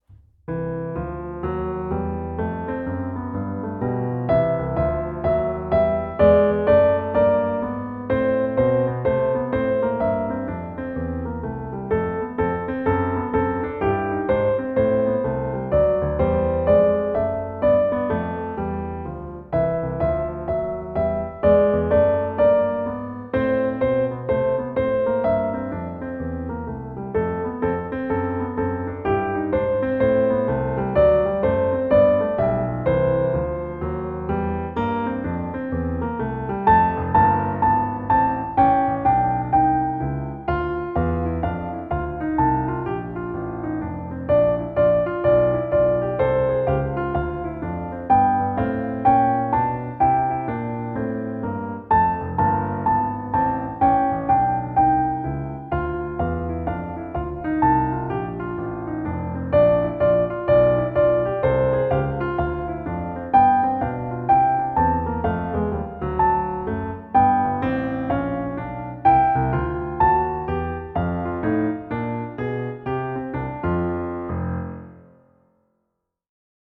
A lively, two-verse setting
• A jazzy, optional duet part
• Performance tempo recording with the optional duet